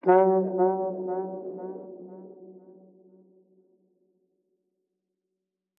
AV_Distorted_Vox
AV_Distorted_Vox.wav